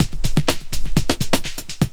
21 LOOP07 -L.wav